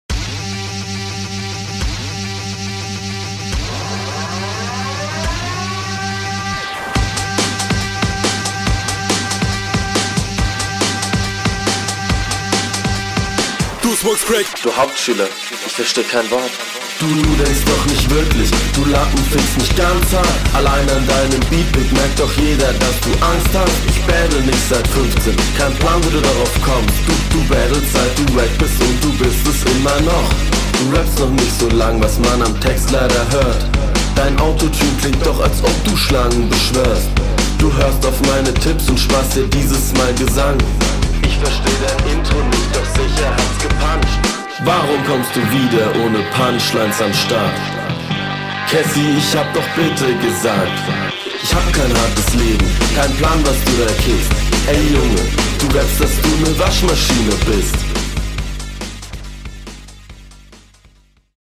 Flow: Schwach, er hat hörbar Probleme mit dem Beat gehabt weswegen es schon sehr weak …
Flow: stimme geht leider ein bisschen unter und der flow ist nicht so gut wie …